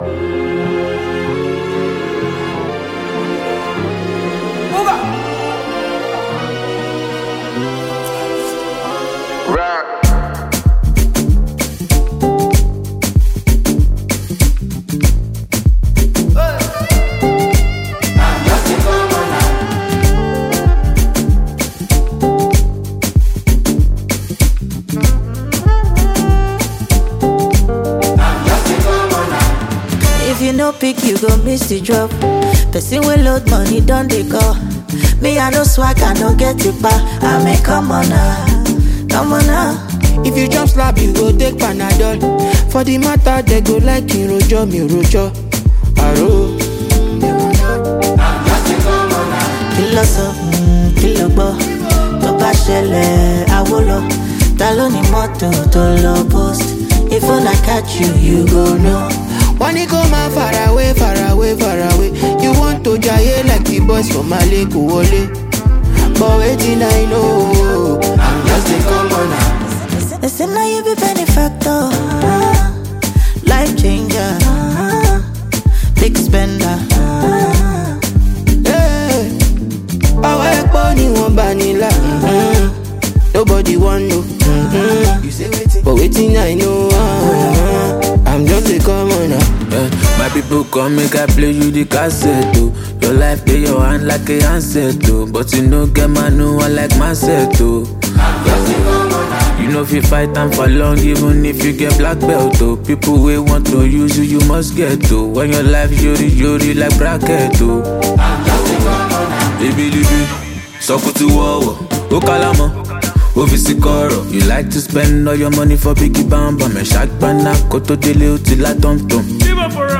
sweet-sounding tune